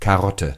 Ääntäminen
Synonyymit Queen Anne's lace Ääntäminen US : IPA : [ˈkær.ət] GenAm: IPA : /ˈkɛɹət/ IPA : /ˈkæɹət/ RP : IPA : /ˈkæɹət/ Haettu sana löytyi näillä lähdekielillä: englanti Käännös Konteksti Ääninäyte Substantiivit 1.